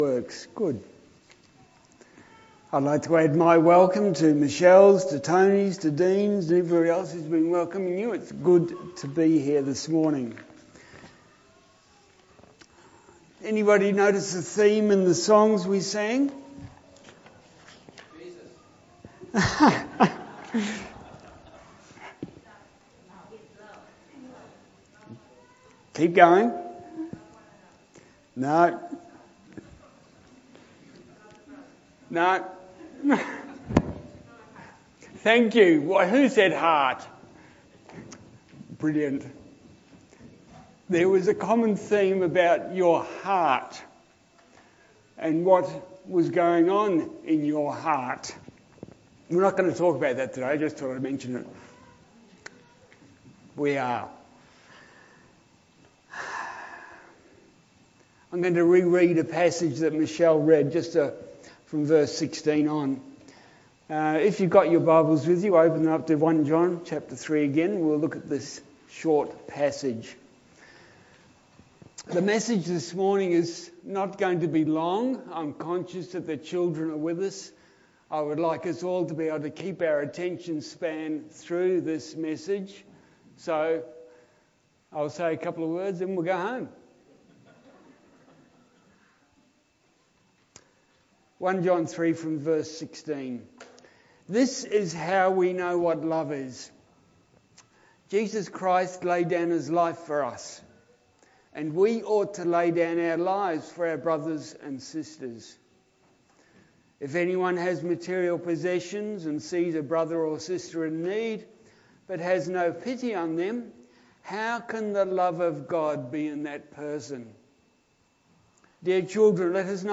Passage: John 3:11-24 Service Type: Sunday Morning